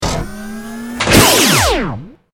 battlesuit_medlaser.ogg